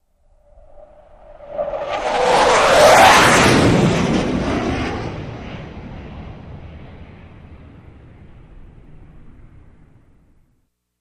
F-16 Fighting Falcon
F-16 By Fast